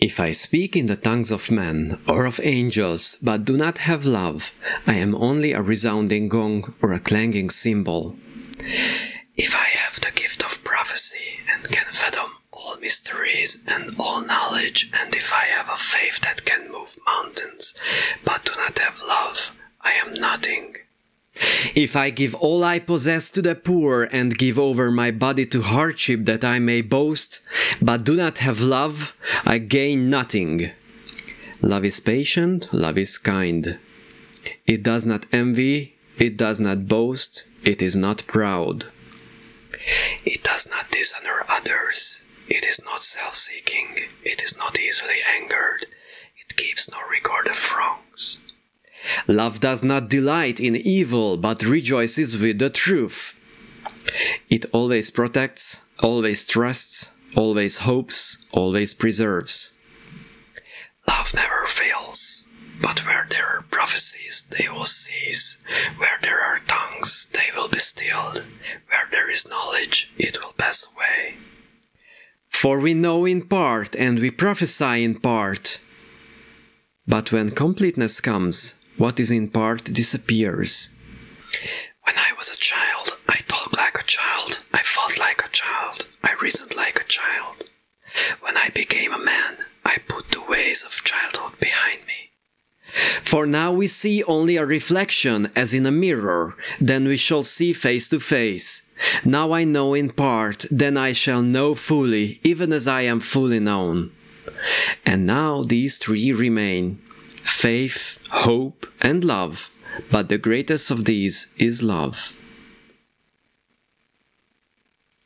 This is why our example audio consists of different speaking strengths, so that we could see the dramatic effect of this filter.
Now, if we want to make the result a bit louder, we could increase the previous step’s makeup argument, or leverage the volume filter.